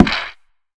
creaking_heart_hit2.ogg